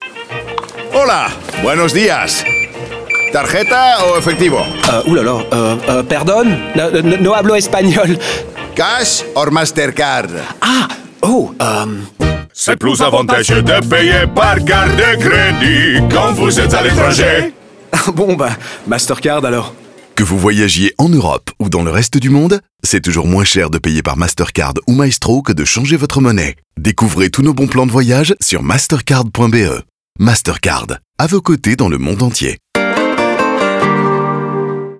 His warm, deep voice and acting skills deserve your attention...